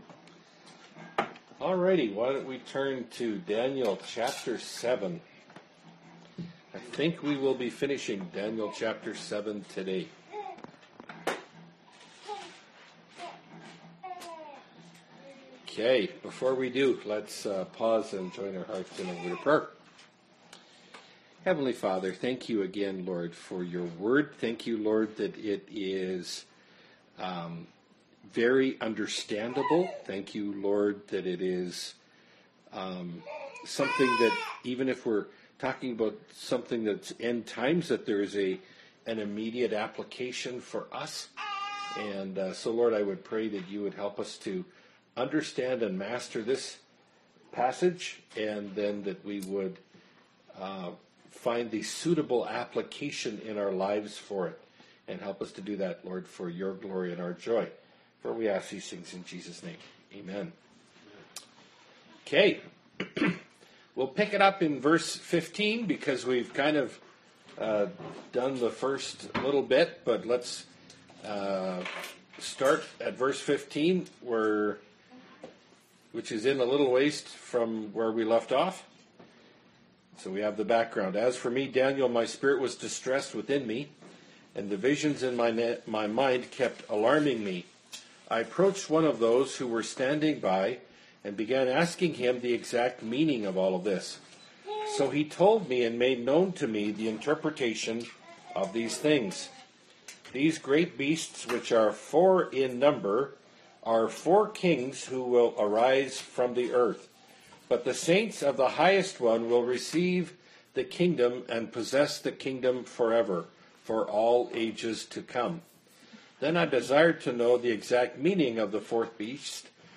Bible Study – Daniel 7 – Part 3 of 3 (2017)
Category: Bible Studies Key Passage: Daniel 7